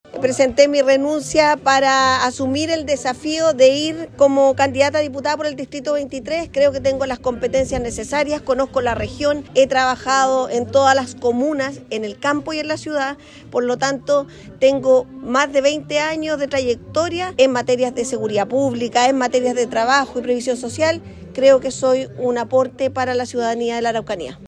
En conversación con Radio Bío Bío, la propia Claudia Tapia dijo que tiene las competencias y experiencias para asumir este desafío.